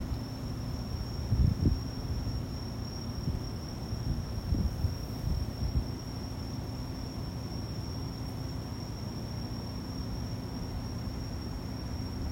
早くもセミの声は聞こえなくなり、すっかり
秋の虫の声です。